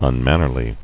[un·man·ner·ly]